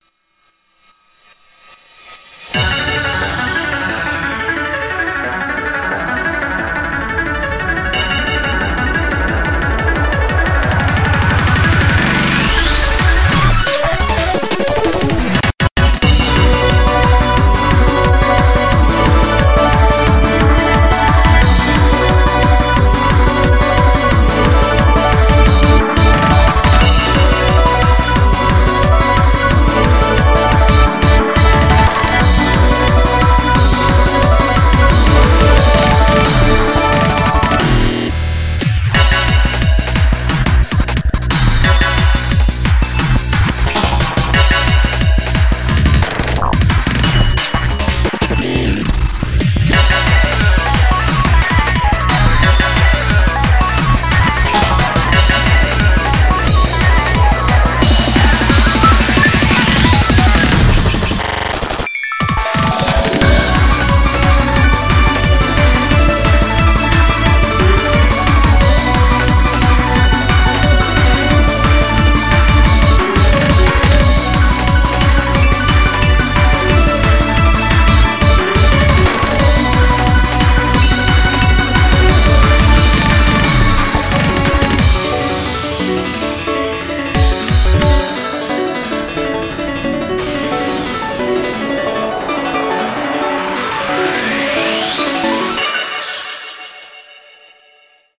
BPM356
Audio QualityLine Out